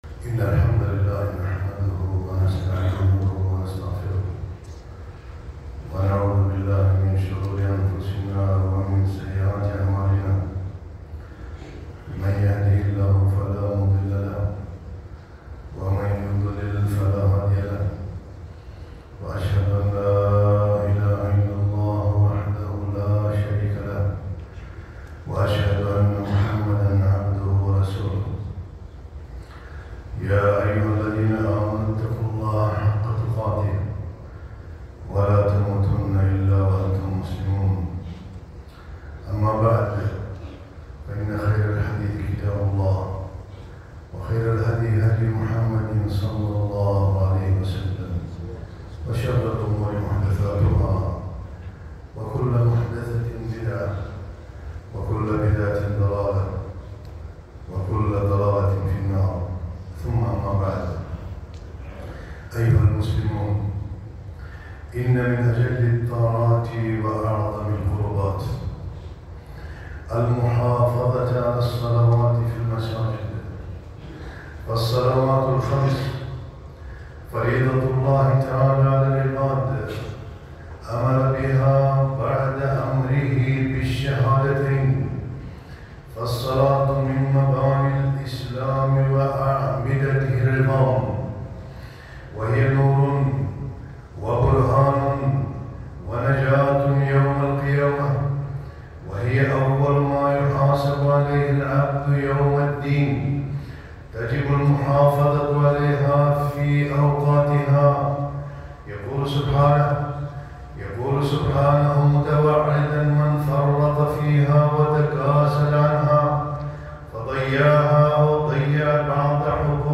خطبة - صلاة الفجر